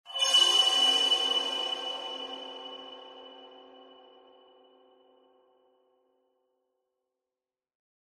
страшный скрежет металла в темной пустой комнате